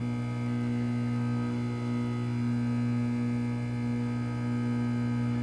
neon50p.wav